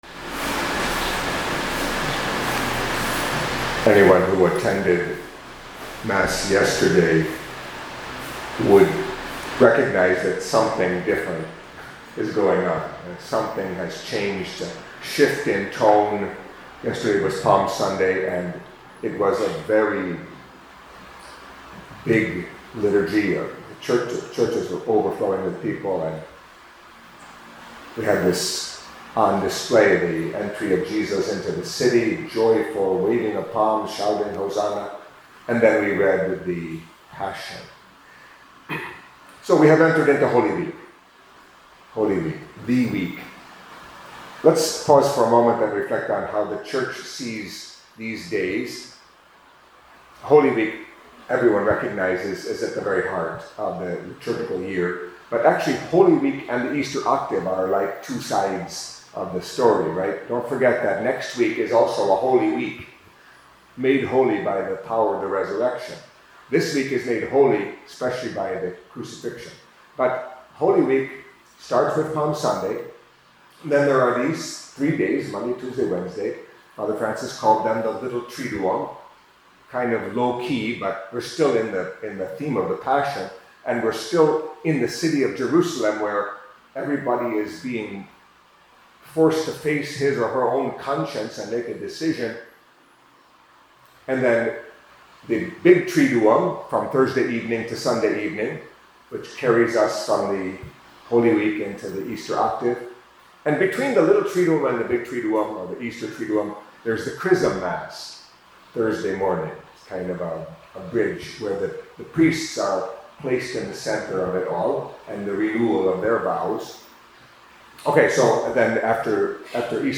Catholic Mass homily for Monday of Holy Week